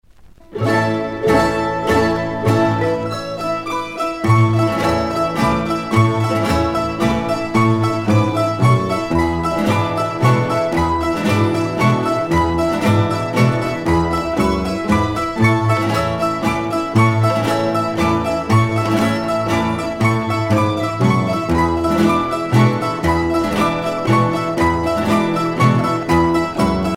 danse : jota (Espagne)
Alto Aragon (Grupo folklorico)
Pièce musicale éditée